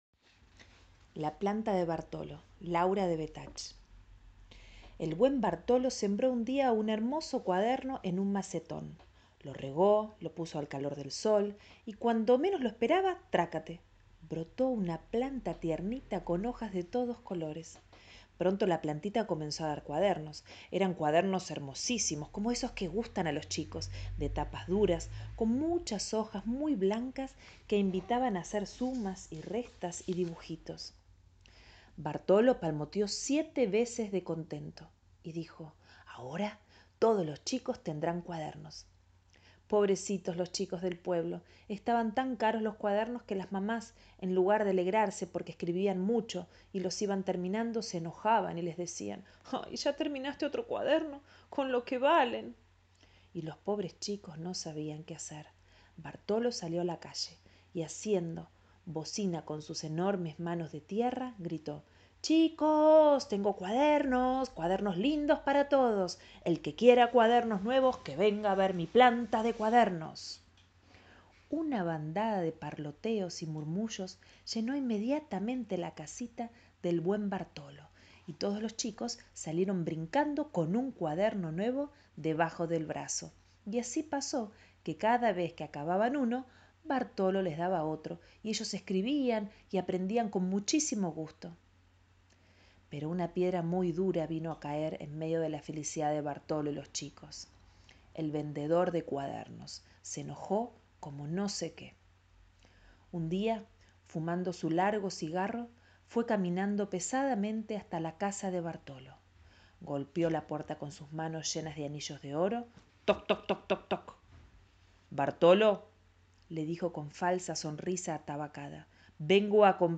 Presentar el cuento «La planta de Bartolo» de Laura Devetach